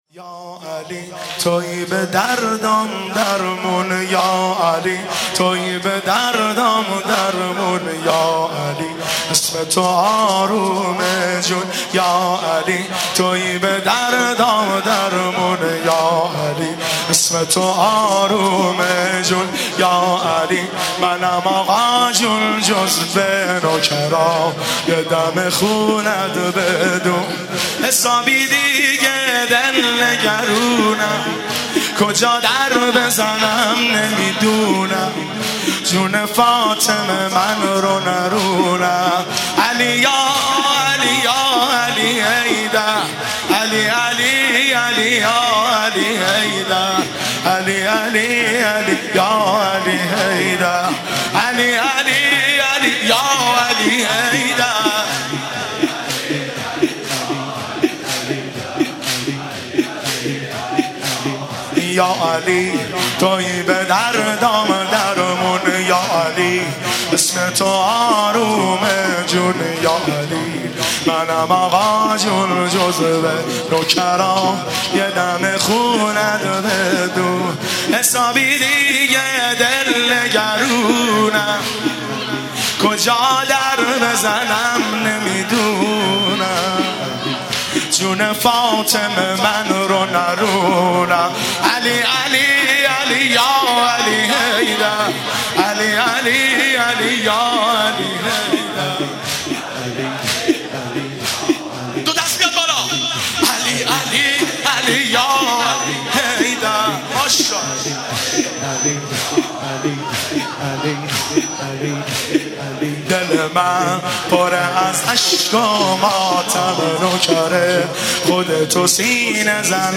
مداح
شب بیست و سوم رمضان - شب قدر سوم